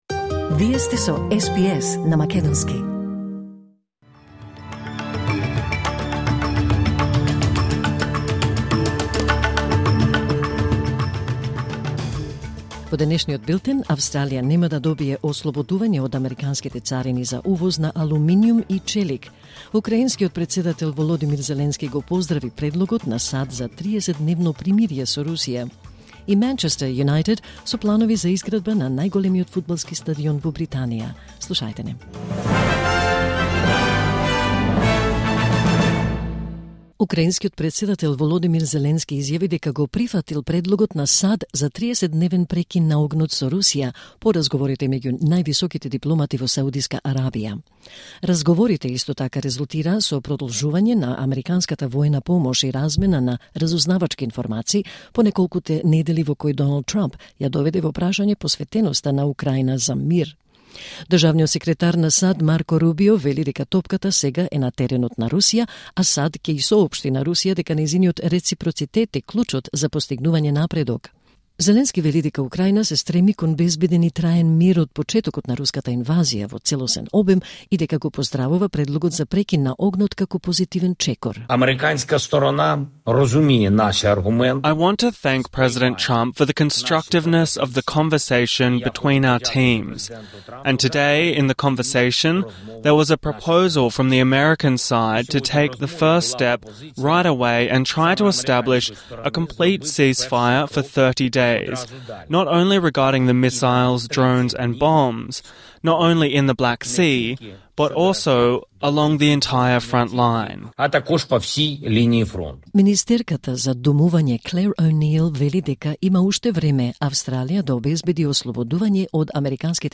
Вести на СБС на македонски 12 март 2025